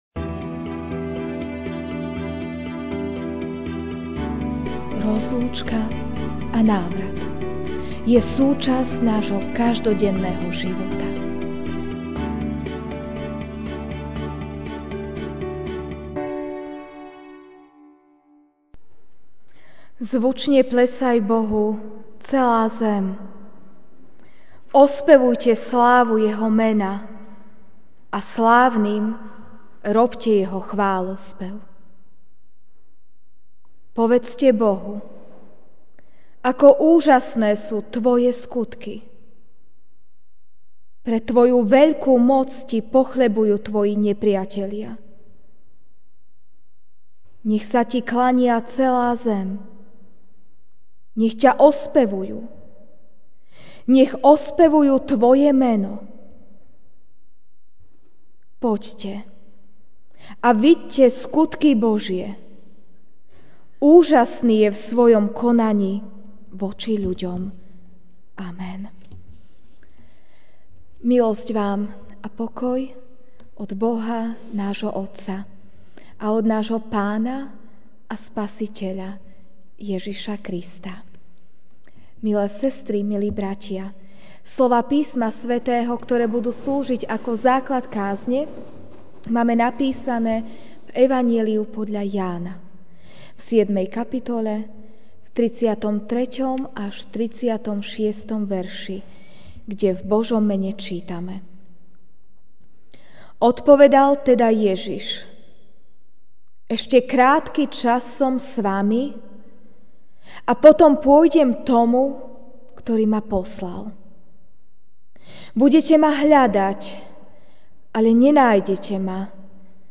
Večerná kázeň: Rozlúčka & návrat (J 7, 33-36) Odpovedal teda Ježiš: Ešte krátky čas som s vami a potom pôjdem k Tomu, ktorý ma poslal.